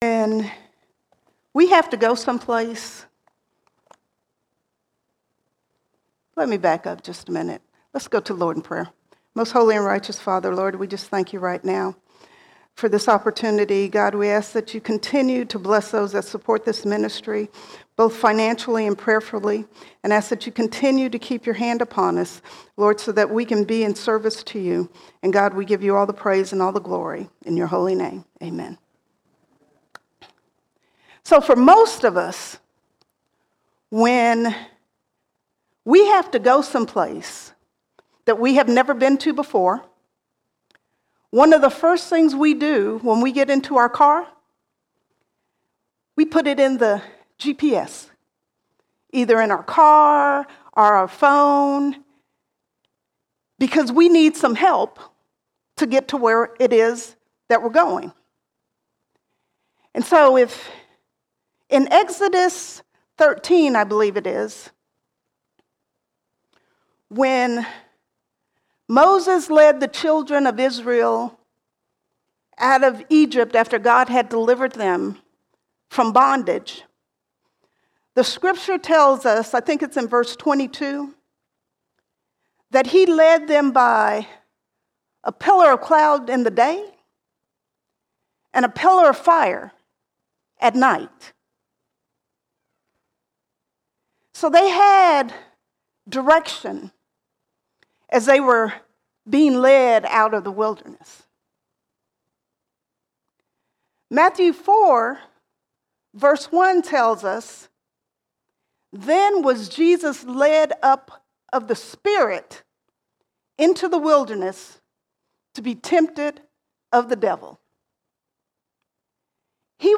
5 August 2024 Series: Sunday Sermons Topic: Holy Spirit All Sermons The Right Direction The Right Direction We have an internal GPS that leads us into the things of God.